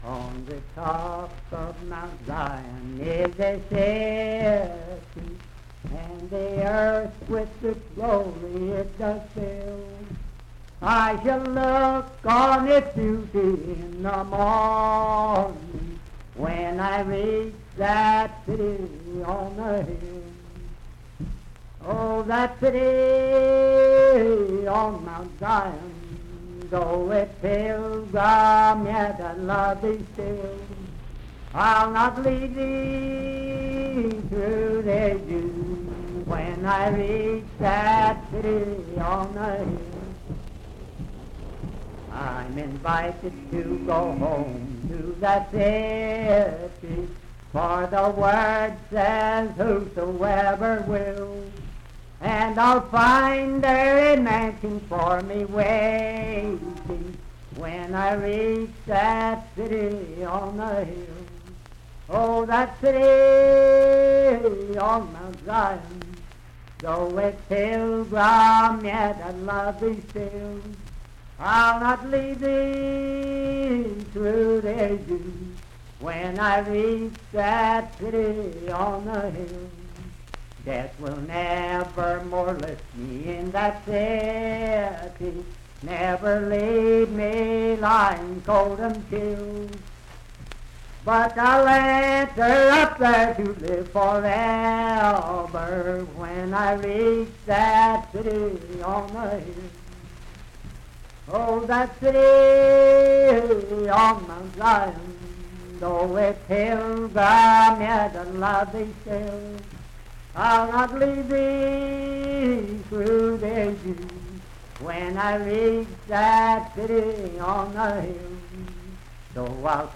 Unaccompanied vocal music
Verse-refrain 4(4) & R(4).
Hymns and Spiritual Music
Voice (sung)
Wood County (W. Va.), Parkersburg (W. Va.)